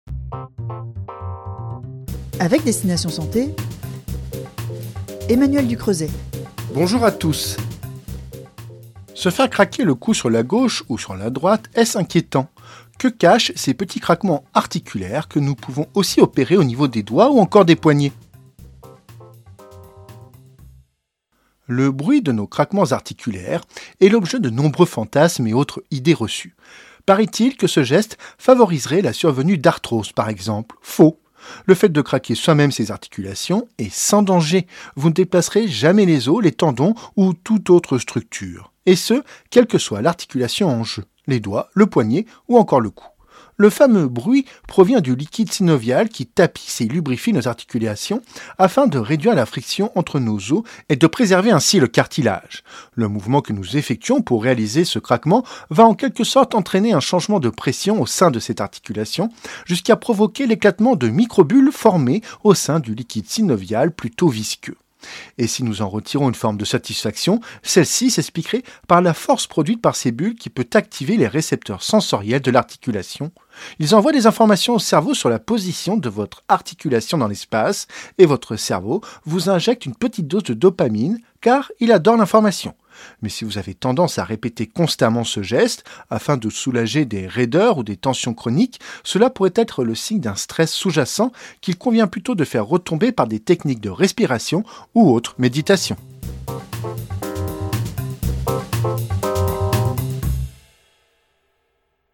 Comme chaque jour la chronique Destination Santé vous propose un  sujet différent sur le Livre Radio et en Podcat.